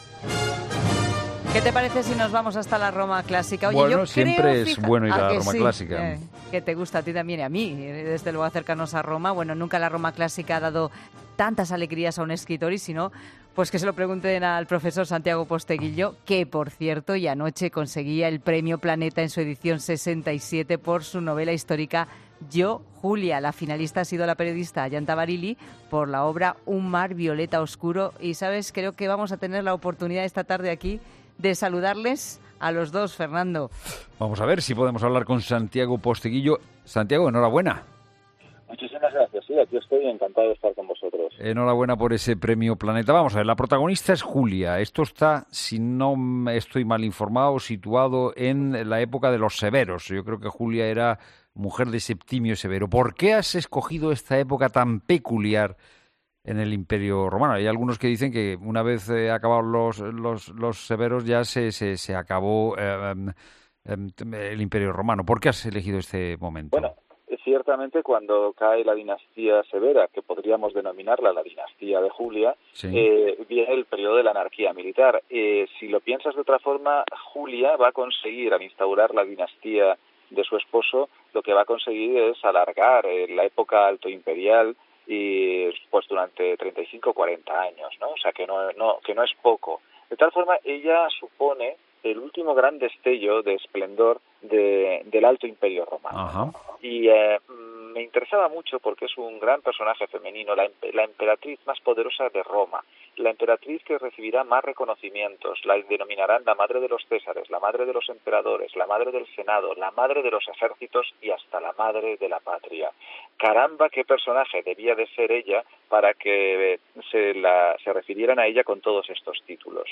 Santiago Posteguillo y Ayanta Barilli, premio Planeta y finalista, en 'La Tarde'
Los flamantes ganador y finalista del Premio Planeta, Santiago Posteguillo y Ayanta Barilli, hablan en 'La Tarde' de sus obras premiadas, 'Yo, Julia' y 'Un mar violeta oscuro'.